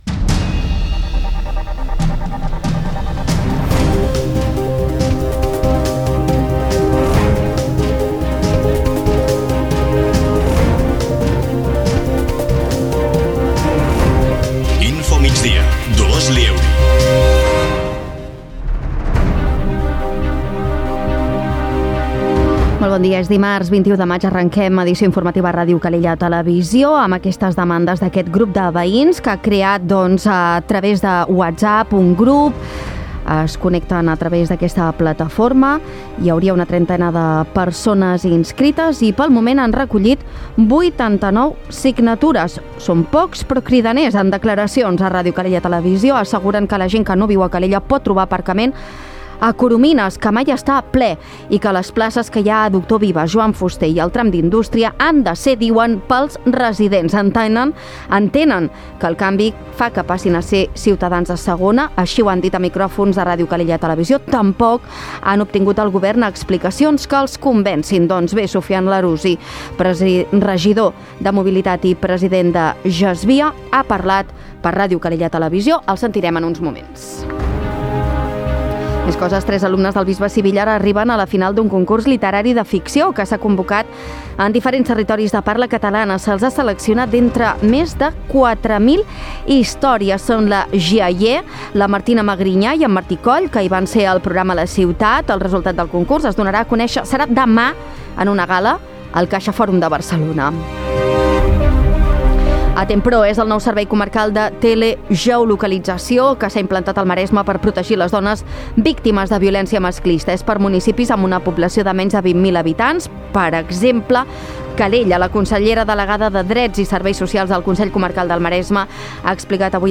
Notícies d’actualitat local i comarcal.